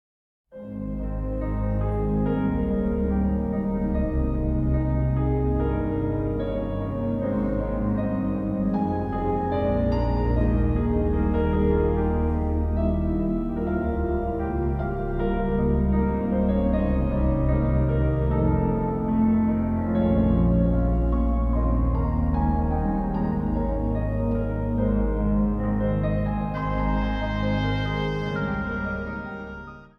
Instrumentaal | Hobo
Instrumentaal | Panfluit
Instrumentaal | Trompet